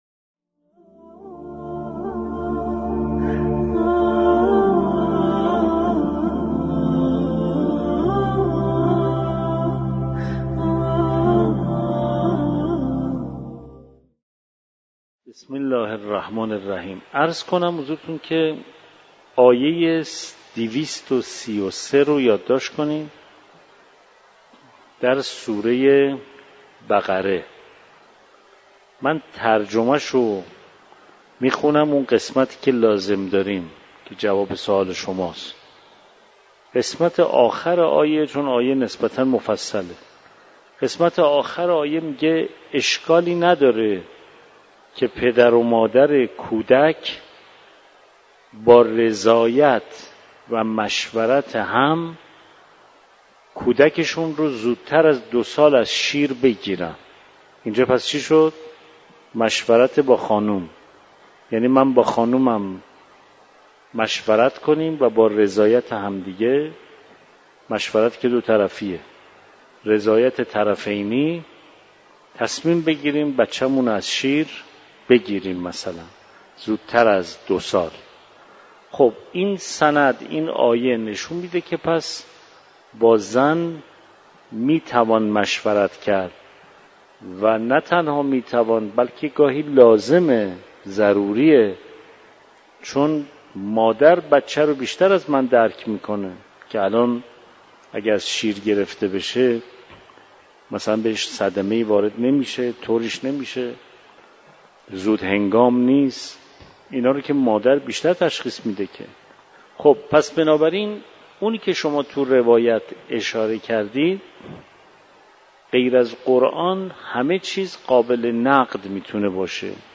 مکان: دارالتفسیر حرم مطهر رضوی